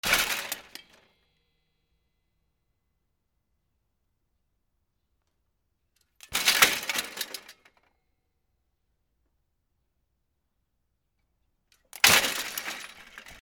落とす 自転車 衝撃
/ H｜バトル・武器・破壊 / H-35 ｜打撃・衝撃・破壊　強_ナチュラル寄り
『ガシャン』